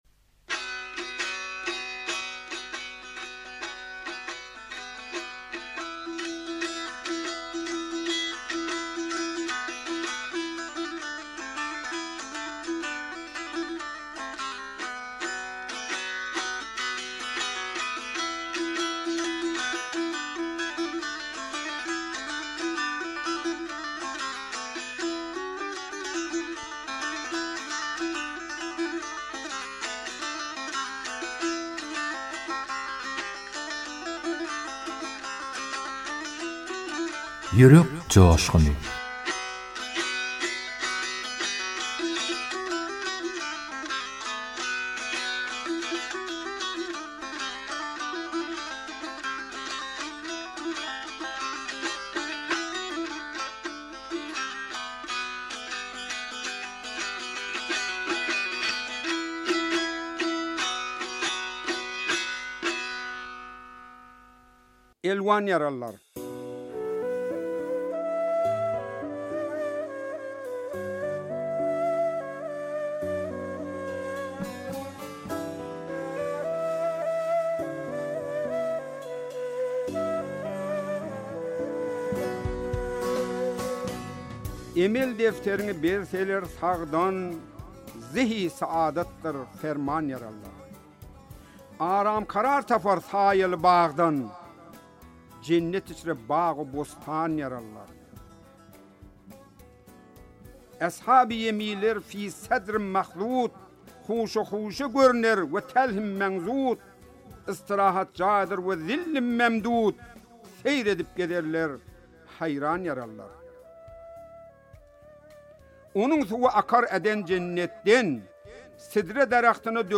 turkmen goşgy owaz şygyrlar